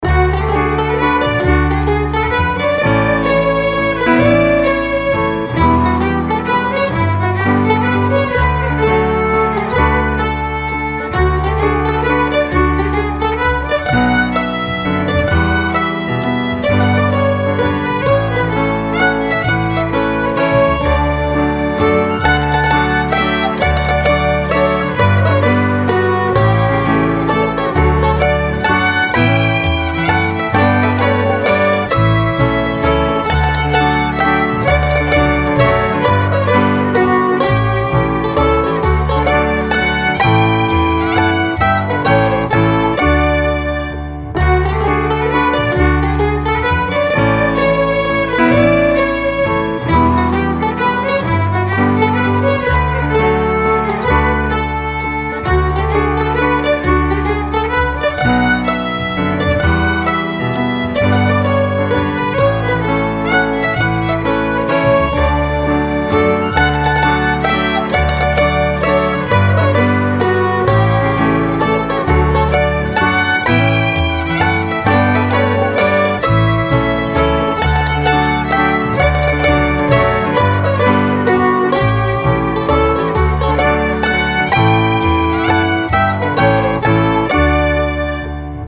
R:Waltz Mazurka
M:3/4
K:D